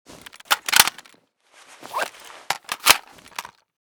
g36_reload.ogg.bak